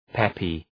Προφορά
{‘pepı}